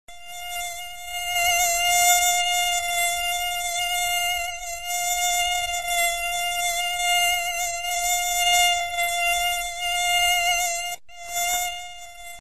Рингтоны » звуки животных » Писк комара